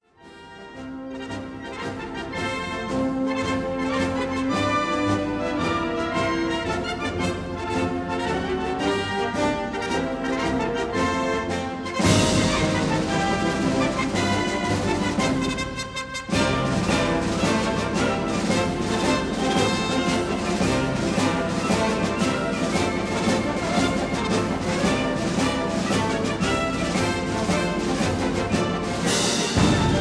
1957 stereo recording